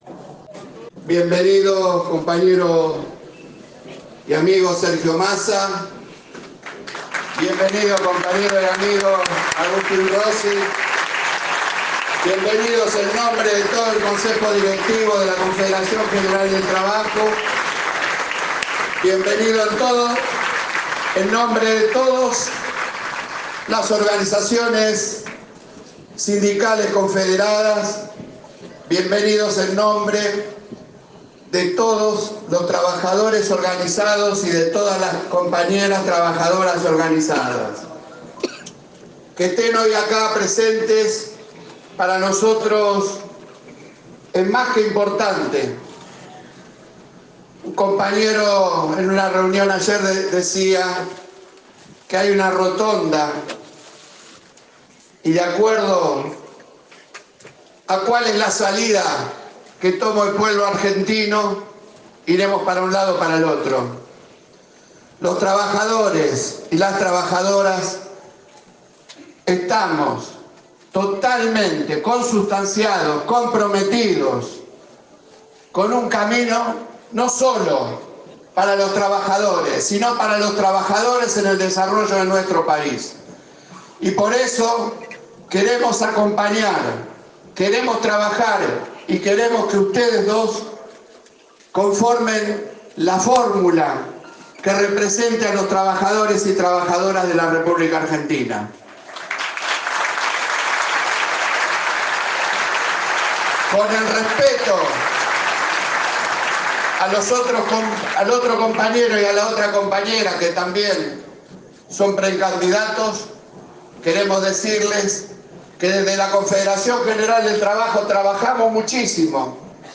En el marco del acto que se llevó adelante en la sede de la Central Obrera del cual participaron el Ministro de Economía y el Jefe de Gabinete, el co-Secretario General de la CGT, Héctor Daer, explícito el apoyo de las y los trabajadores organizados a la fórmula presidencial que componen ambos funcionarios.